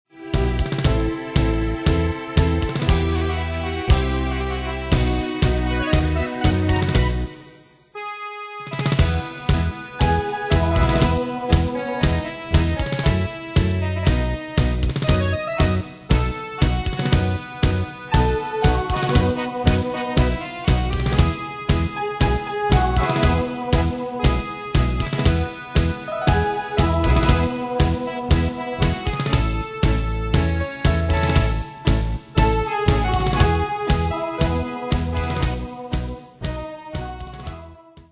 Danse/Dance: Tango Cat Id.
Pro Backing Tracks